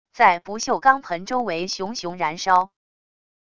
在不锈钢盆周围熊熊燃烧wav音频